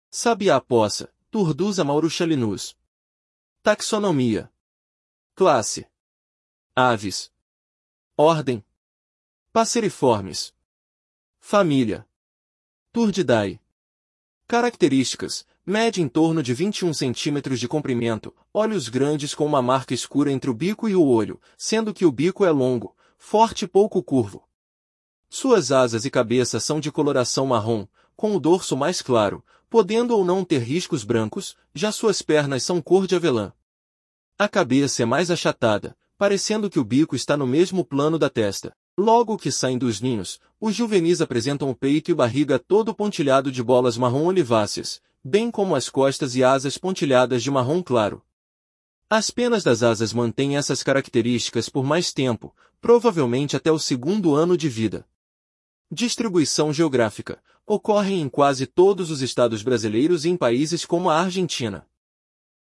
Sabiá-poca (Turdus amaurochalinus)
Ordem Passeriformes